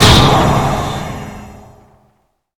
TwilightHitEffectMiddle.ogg